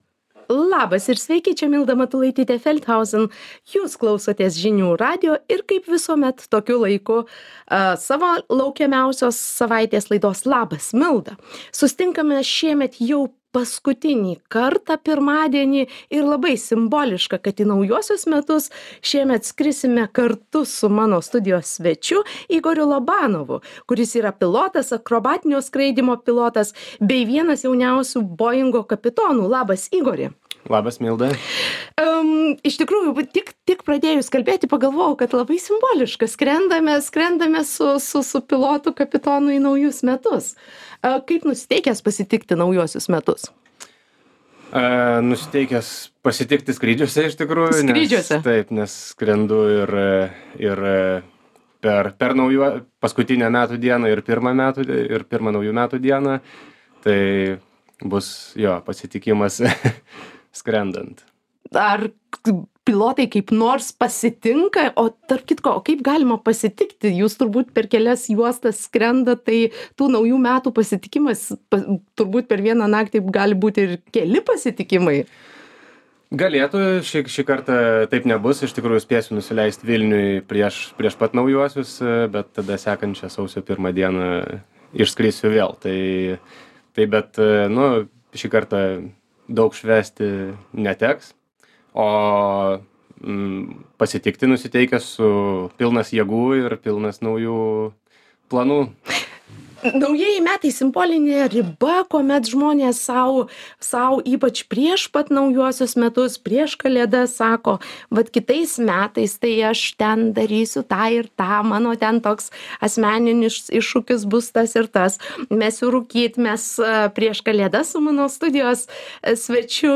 studijoje viešės neeilinis svečias